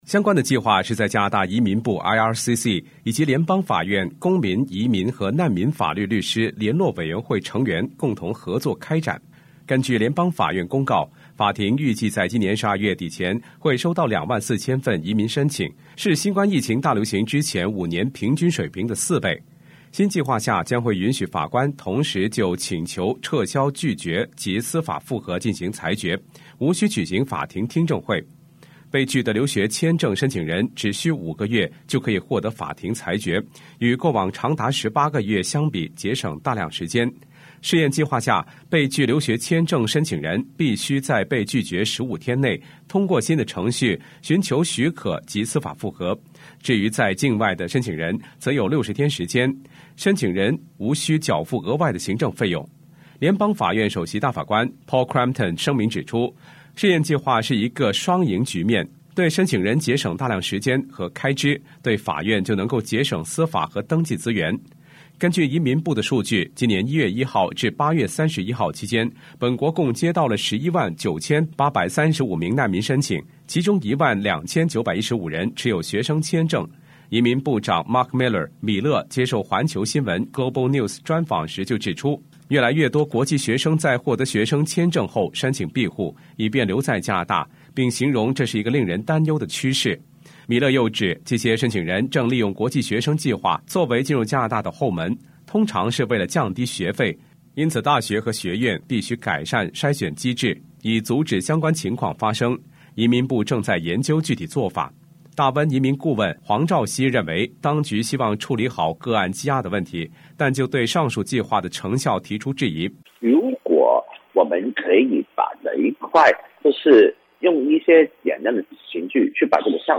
報道